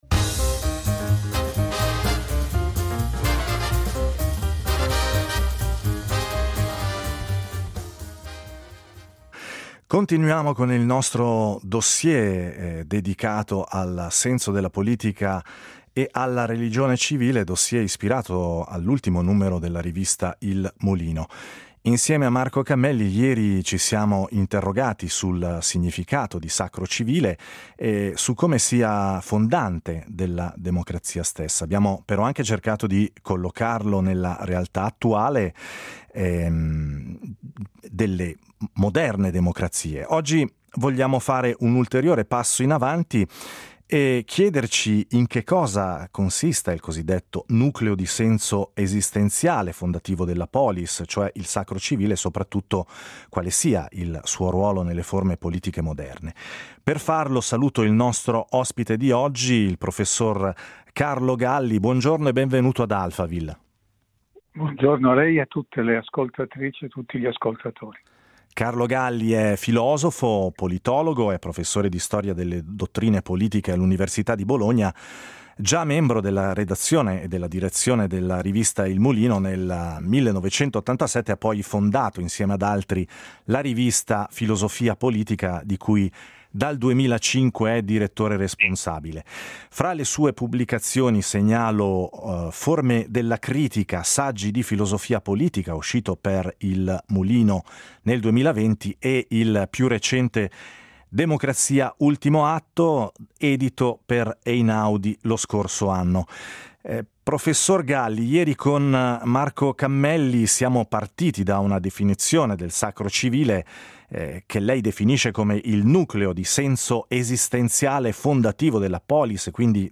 Ai microfoni Carlo Galli, filosofo, politologo e professore di Storia